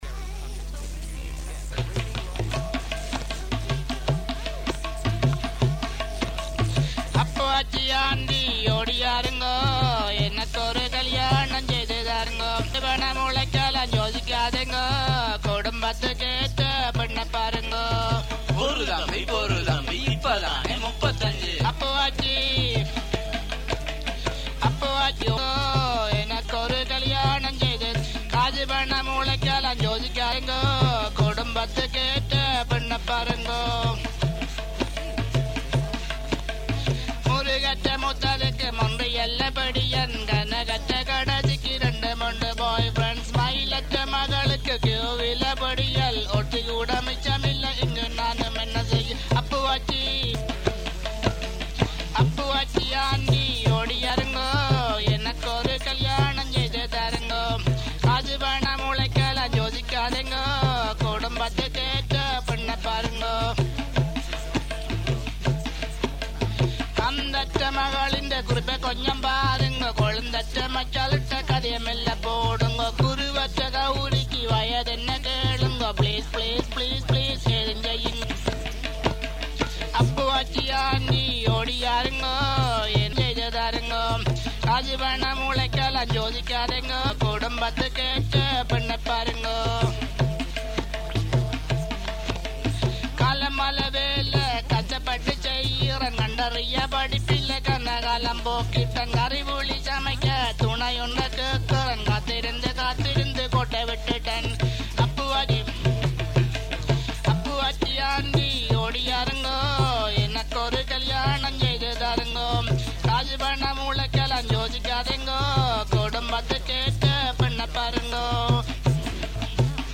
ஆனால் ஒலிப்பதிவு தெளிவு இல்லாமல் இருக்கிது.